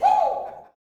WOO  02.wav